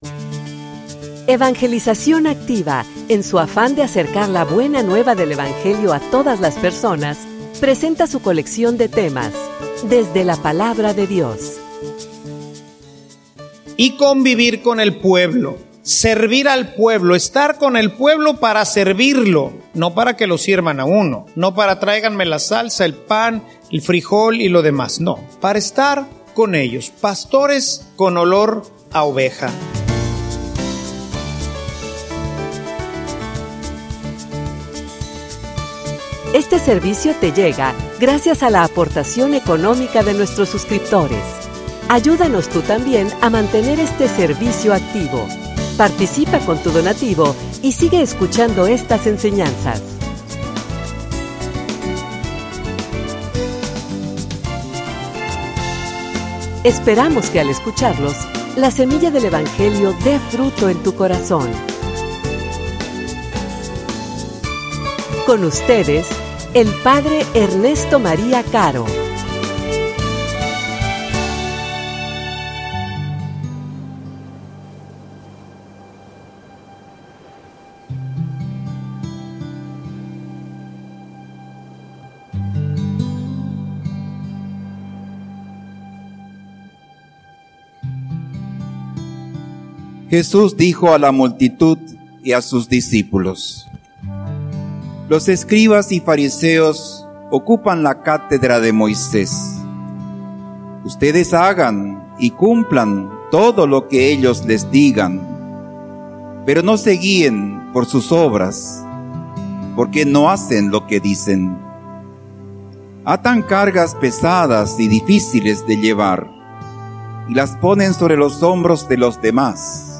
homilia_El_camino_la_humildad.mp3